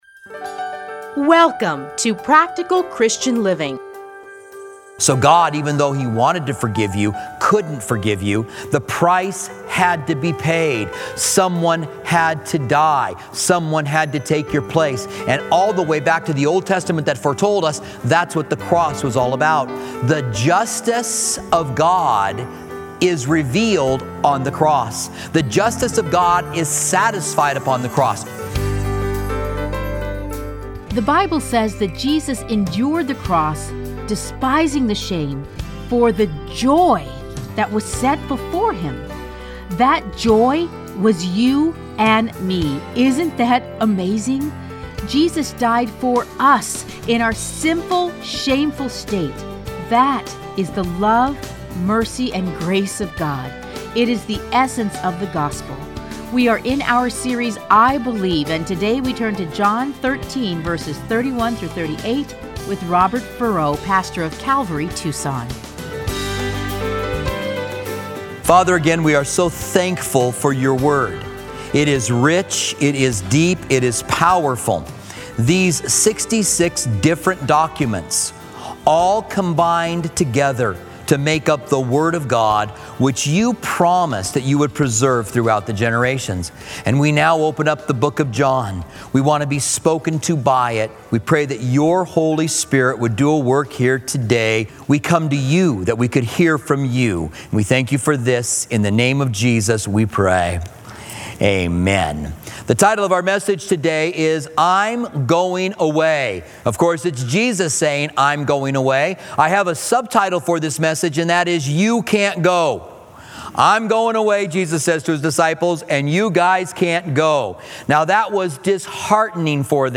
Listen to a teaching from John 13:18-30.